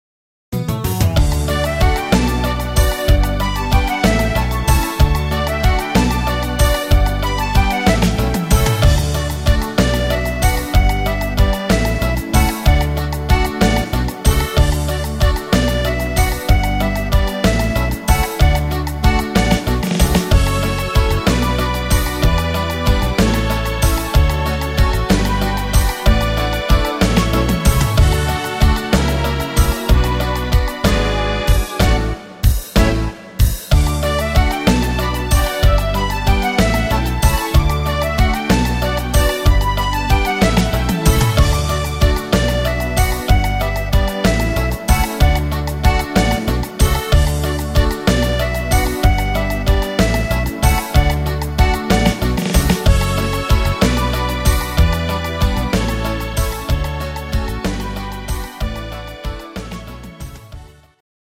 Rhythmus  Waltz
Art  Volkstümlich, Deutsch